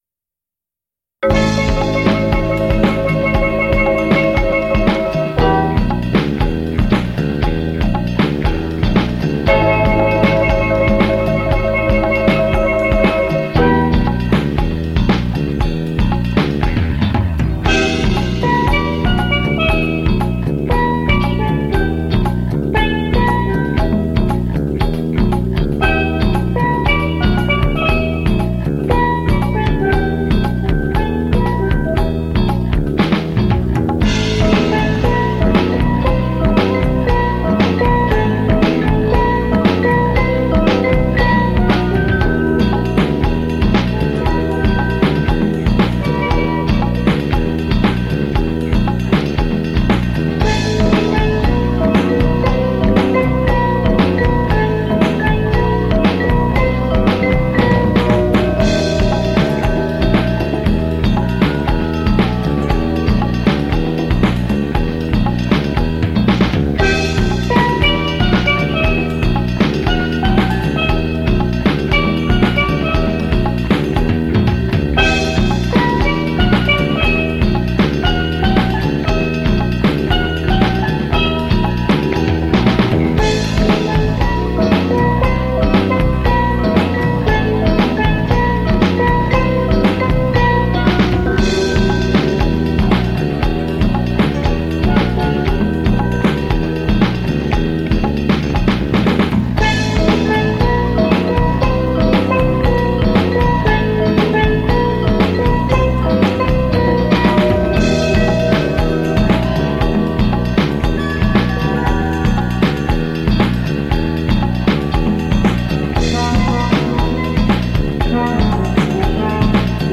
lead tenor pan, vibes, marimba, and vocals
double seconds pan
keyboards and percussion
electric bass